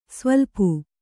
♪ svaḷpu